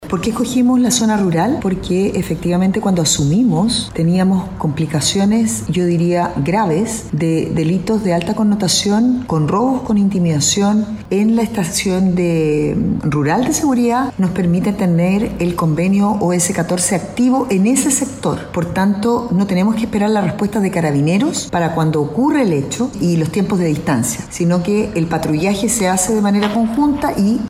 En este contexto, la alcaldesa de Quilpué, Carolina Corti, señaló que se implementará una nueva Estación de Seguridad Rural en el sector de Colliguay, la que se suma a la Estación de Seguridad Pública Municipal La Retuca, implementada desde el año 2025.